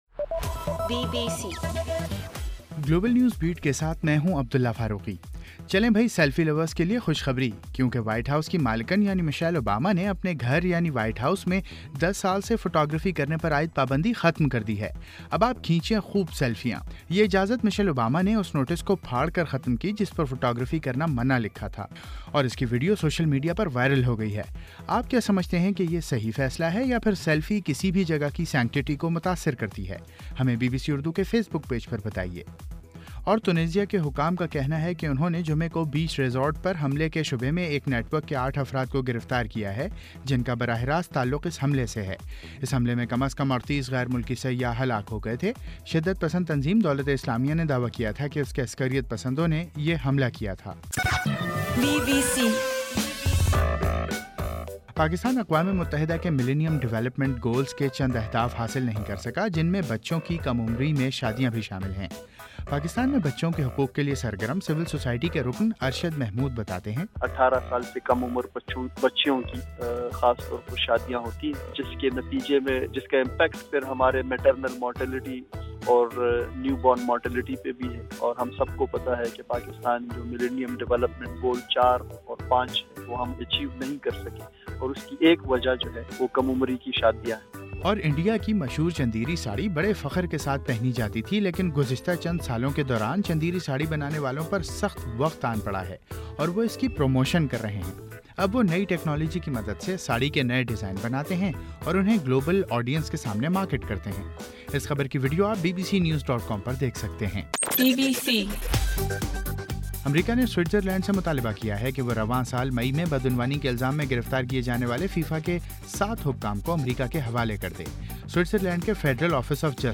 گلوبل نیوز بیٹ بُلیٹن اُردو زبان میں رات 8 بجے سے صبح 1 بجے تک ہر گھنٹے کے بعد اپنا اور آواز ایف ایم ریڈیو سٹیشن کے علاوہ ٹوئٹر، فیس بُک اور آڈیو بوم پر ضرور سنیے۔